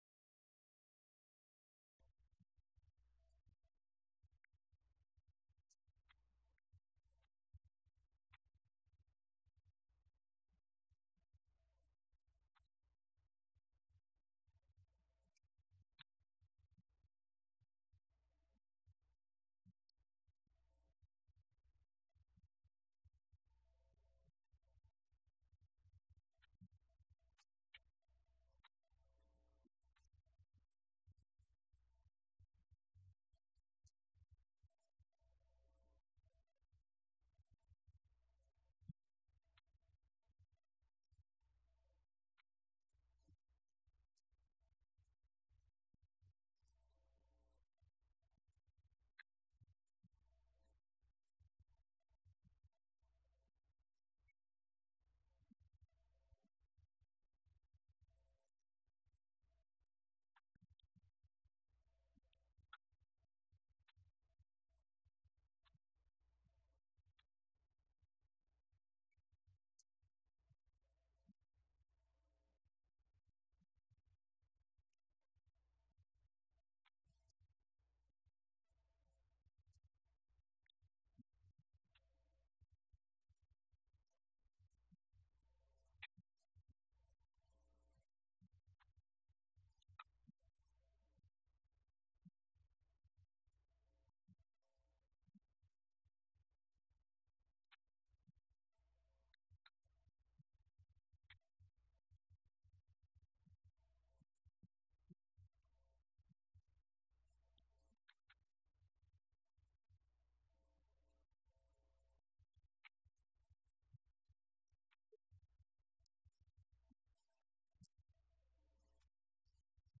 Event: 17th Annual Schertz Lectures Theme/Title: Studies in Job